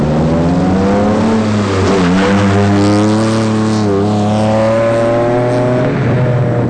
First and second gear accel